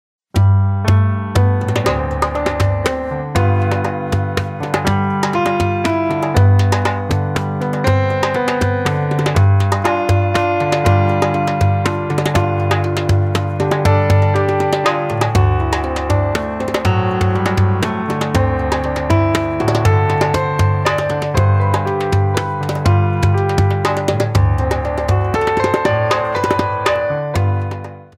Percussion and Piano Music